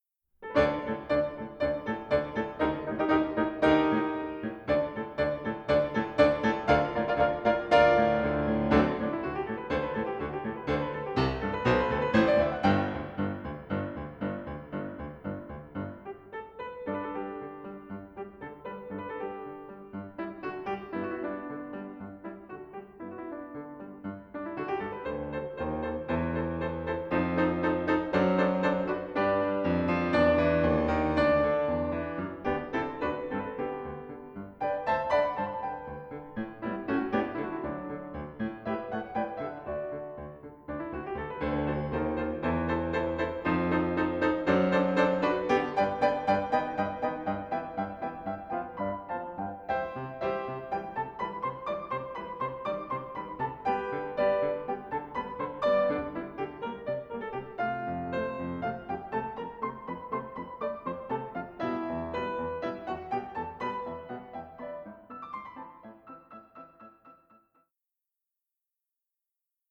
these solo piano works, both charming and imposing